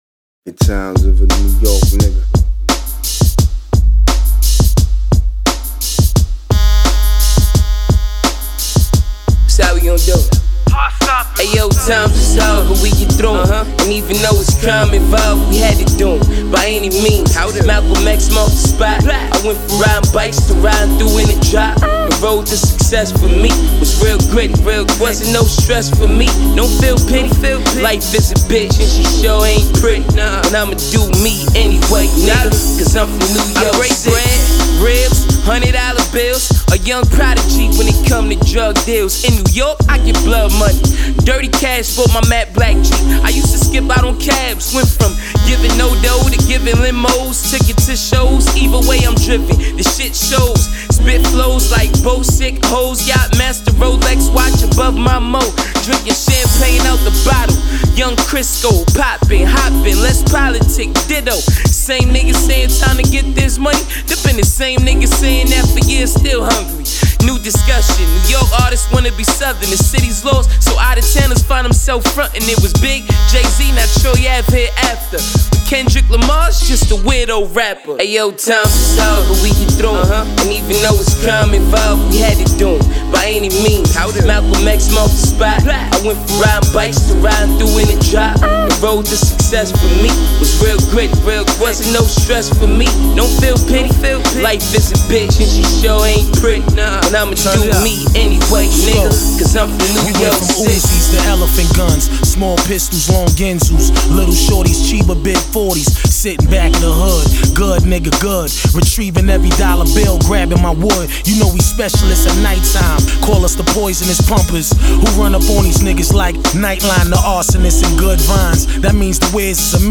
quintessential NYC boom-bap track